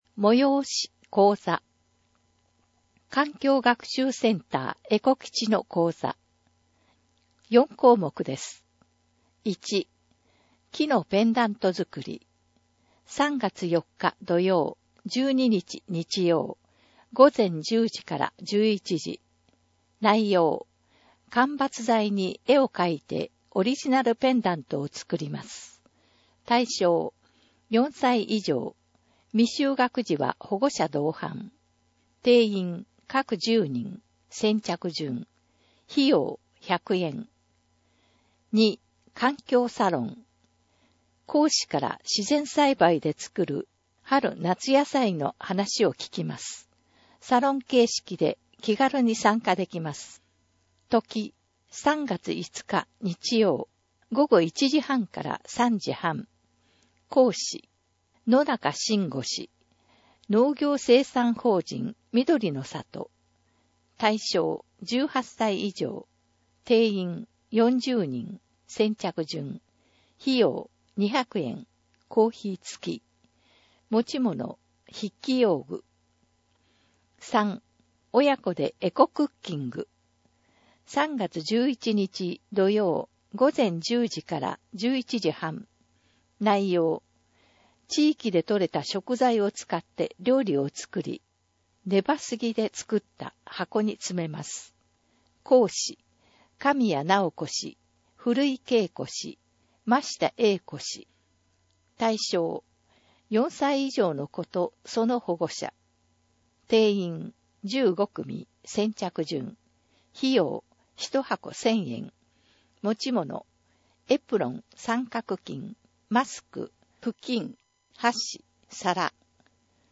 広報あんじょうの音声版を公開しています
なお、以上の音声データは、「音訳ボランティア安城ひびきの会」の協力で作成しています。